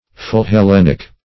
Philhellenic \Phil`hel*len"ic\, a.